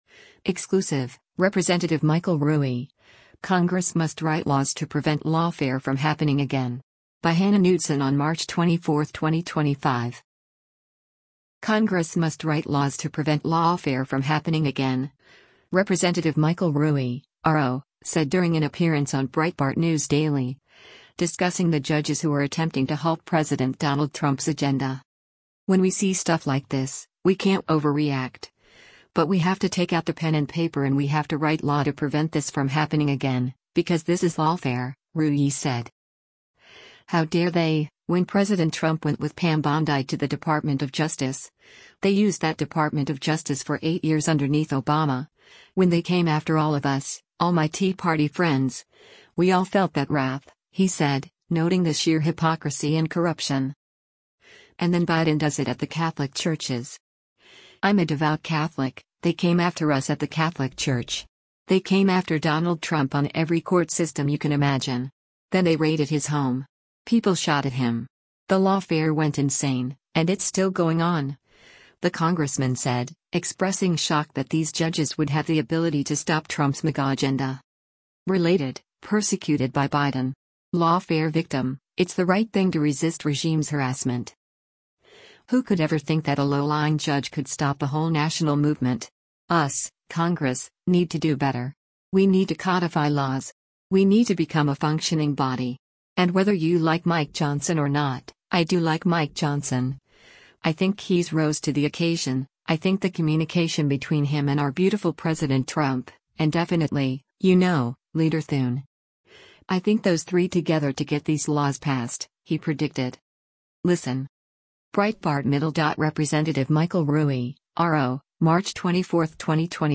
Congress must write laws to “prevent” lawfare from happening again, Rep. Michael Rulli (R-OH) said during an appearance on Breitbart News Daily, discussing the judges who are attempting to halt President Donald Trump’s agenda.